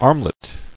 [arm·let]